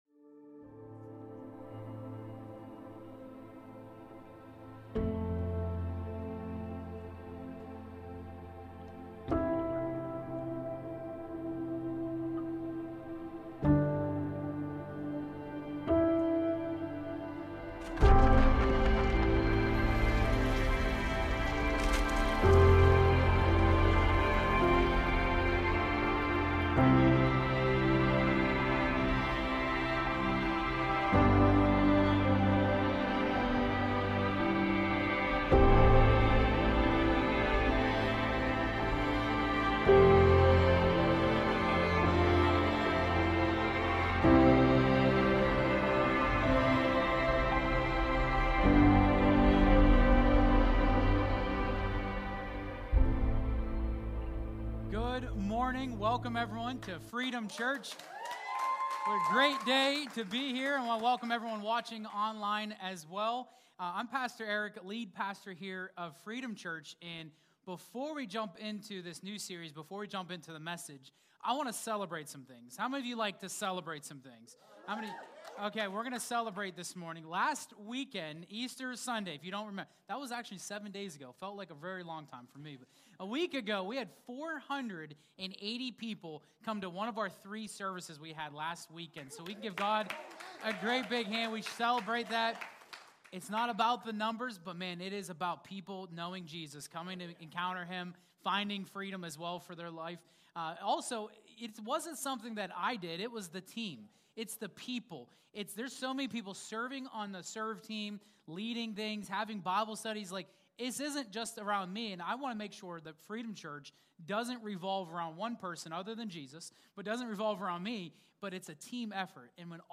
Sunday Service
freedom_church_live_v1-1080p.mp3